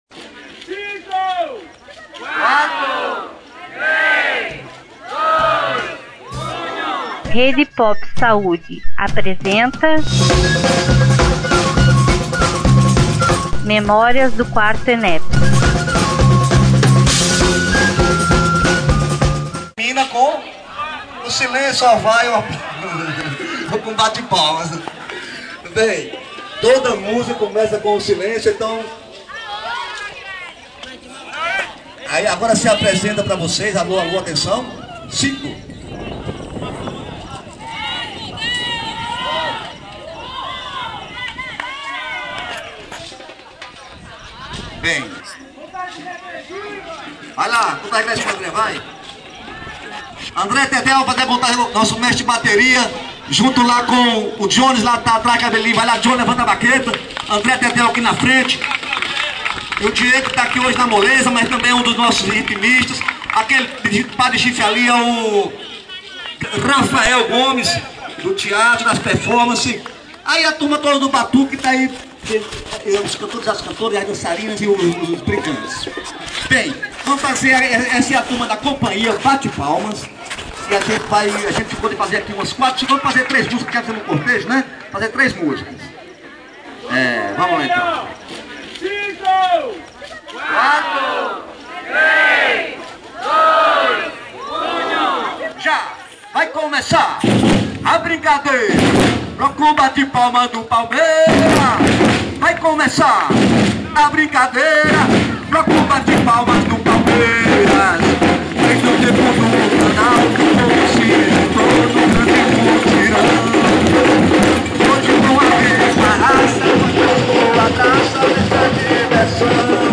Entrevista com a Companhia Bate Palmas do Conj. Palmeiras - Ceará 1